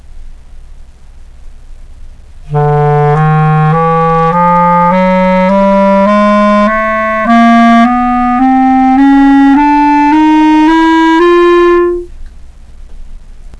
A Chromatic Scale , as performed on clarinet by the up-and-coming clarinetist
chromatic_slurred.wav